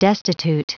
Prononciation du mot destitute en anglais (fichier audio)
Prononciation du mot : destitute